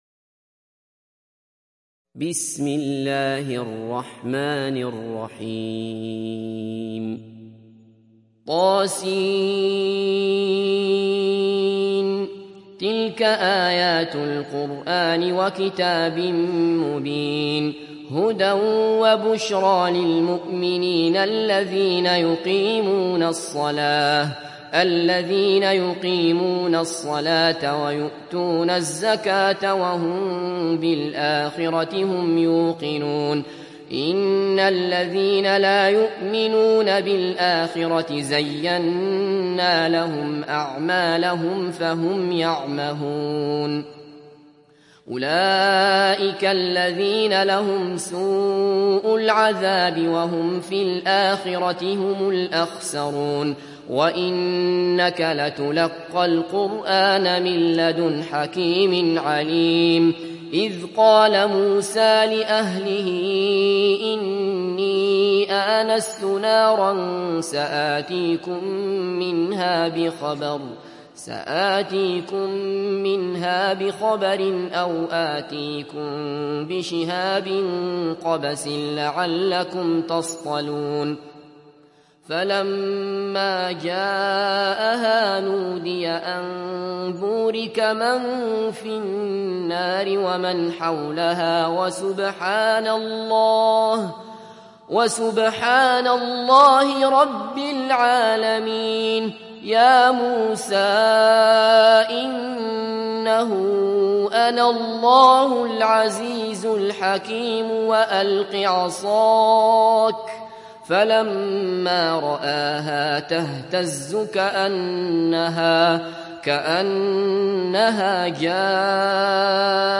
دانلود سوره النمل mp3 عبد الله بصفر روایت حفص از عاصم, قرآن را دانلود کنید و گوش کن mp3 ، لینک مستقیم کامل